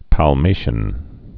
(păl-māshən, päl-, pä-mā-)